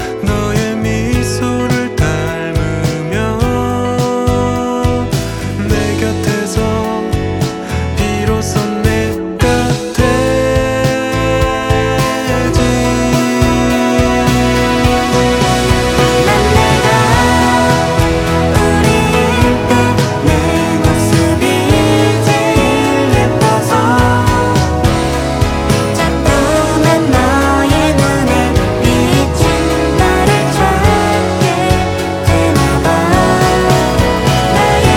Жанр: Поп / K-pop / Музыка из фильмов / Саундтреки